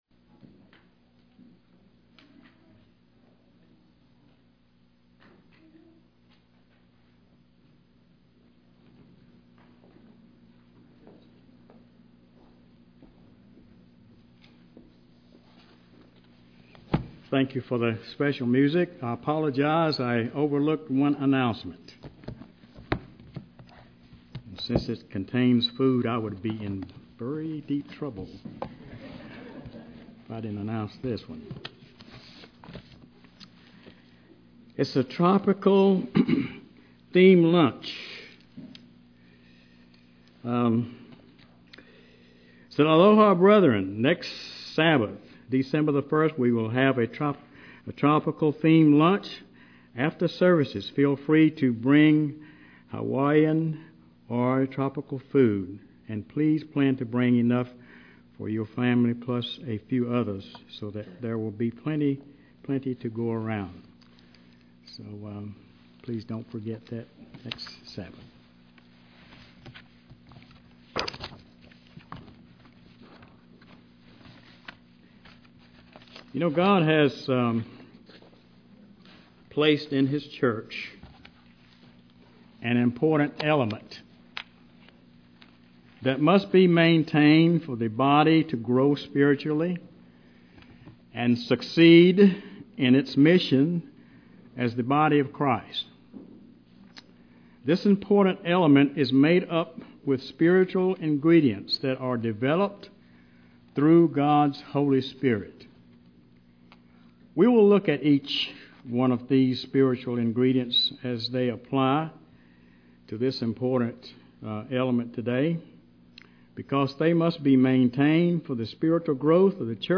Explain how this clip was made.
Given in Charlotte, NC